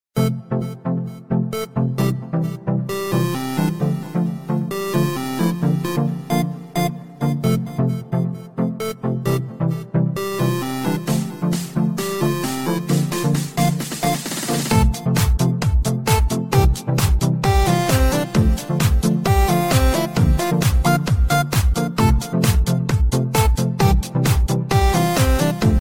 Tono de llamada